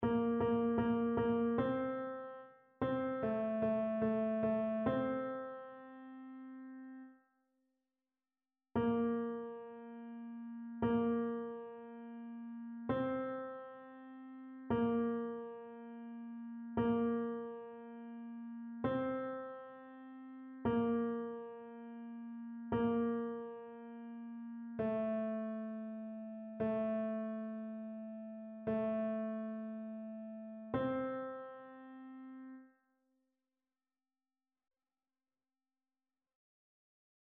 Chœur
TénorBasse
annee-a-temps-ordinaire-29e-dimanche-psaume-95-tenor.mp3